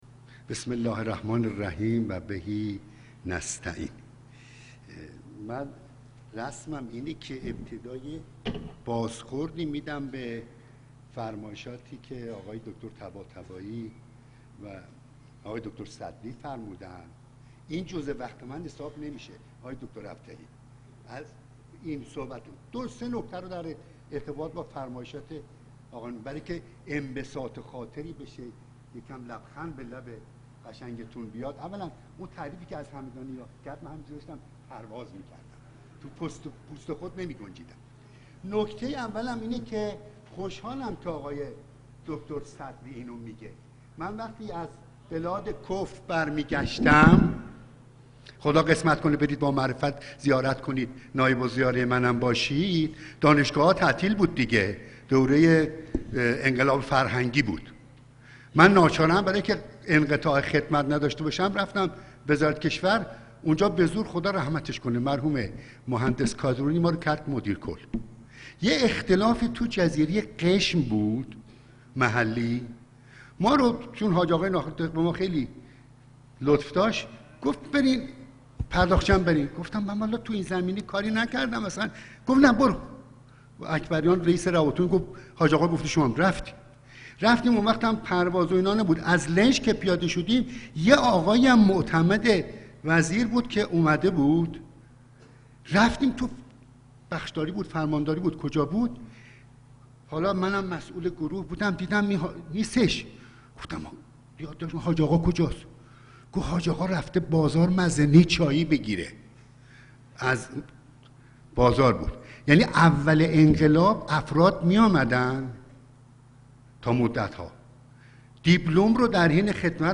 صوت و فایل ارائه گزیده‌ای از سخنرانی‌های ششمین کنفرانس ملی آموزش و توسعه سرمایه انسانی که در تاریخ ۷ و ۸ بهمن ماه ۱۳۹۷ توسط انجمن علمی آموزش و توسعه منابع انسانی ایران برگزار گردیده، در این بخش قابل دریافت است.